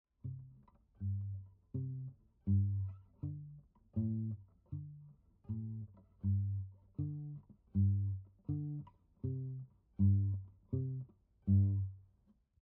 This fingerpicking style is characterized by a repeated bass pattern, while playing chords and melody at the same time.
The bass notes generally alternate between the root and 5th of the chord. We are slightly muting the notes with the palm of the picking hand to give a shorted, thumpy sound.
Travis picking pattern 1: alternating bass notes